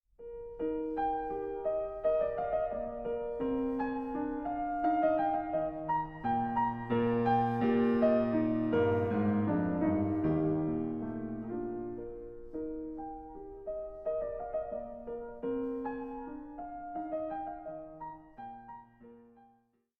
15 Variations and a Fugue in E Flat Major, Op. 35